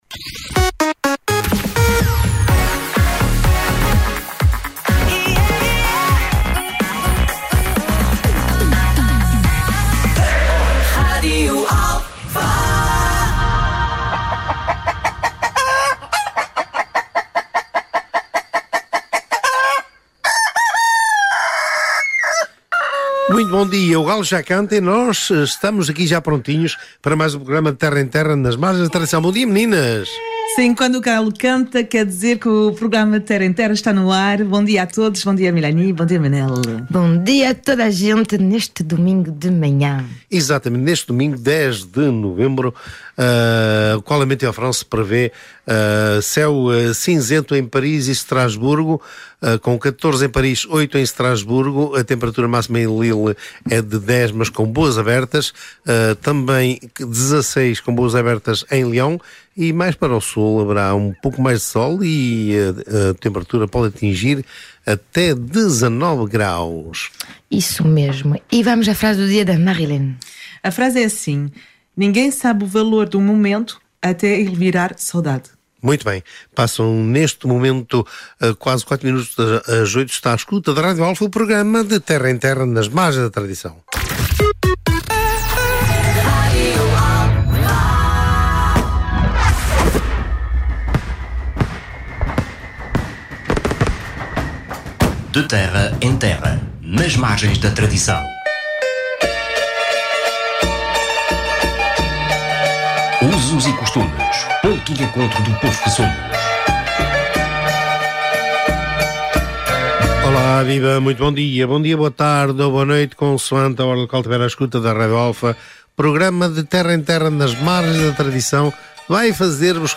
A música tradicional portuguesa e as tradições populares